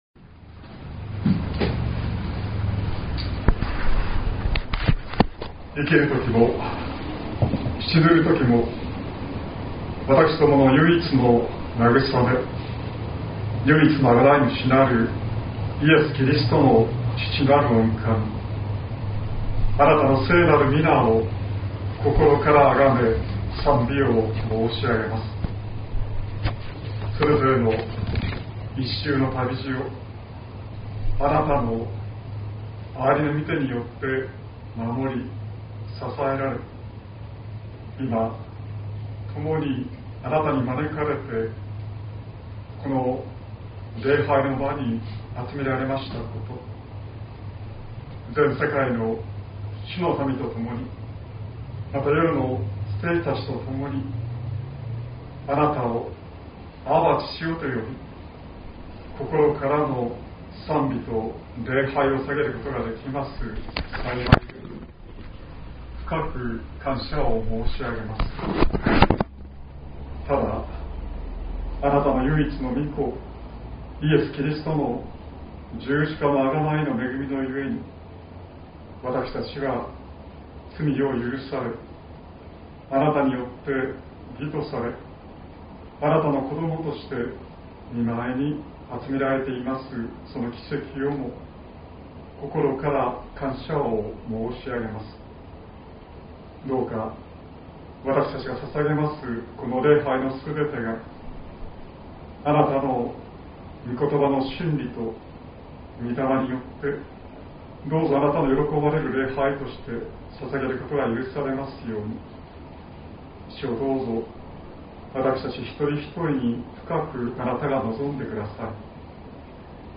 2025年06月22日朝の礼拝「必要なただ一つのこと」西谷教会
説教アーカイブ。
音声ファイル 礼拝説教を録音した音声ファイルを公開しています。